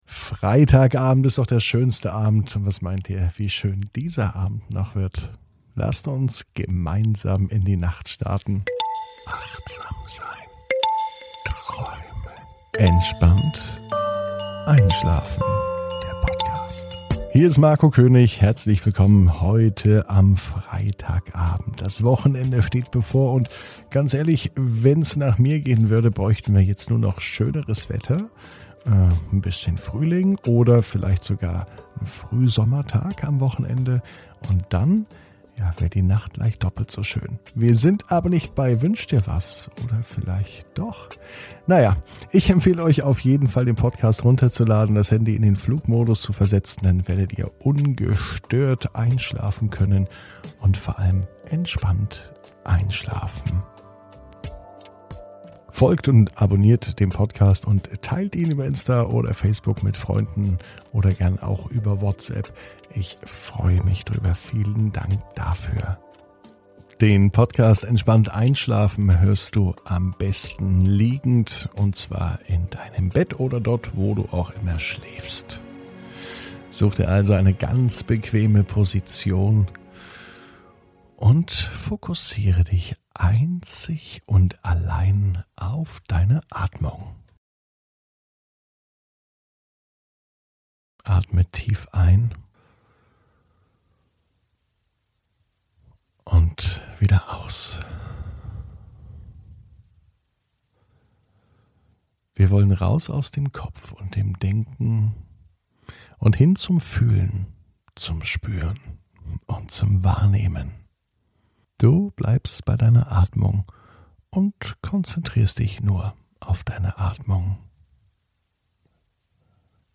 (ohne Musik) Entspannt einschlafen am Freitag, 23.04.21 ~ Entspannt einschlafen - Meditation & Achtsamkeit für die Nacht Podcast